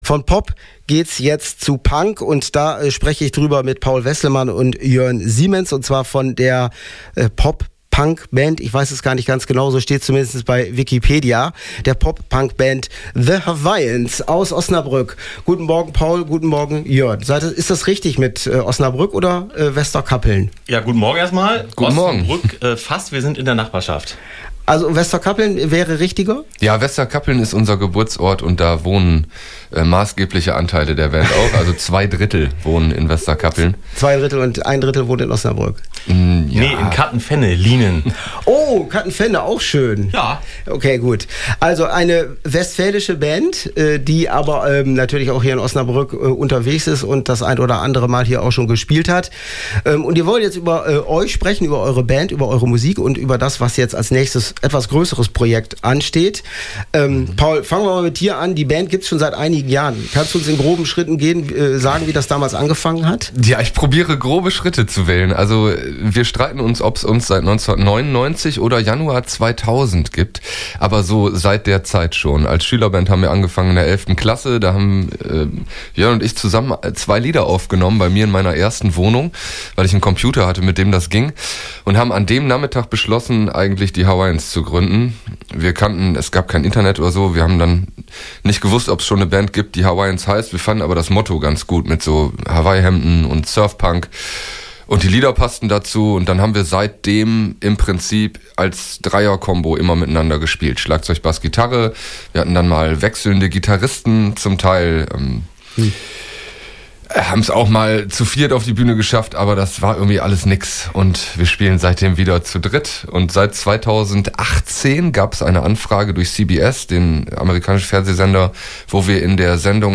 Über ihre Musik, die Band und die geplante Tour durch Südamerika haben The Hawaiins bei uns am 21.02. im Morgenmagazin Startklar berichtet: